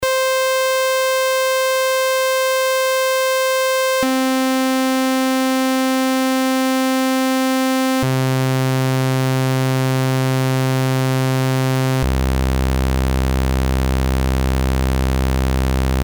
Datei) 632 KB Beispiel Sägezahnsound 1
Beispiel_2_Sägezahn.mp3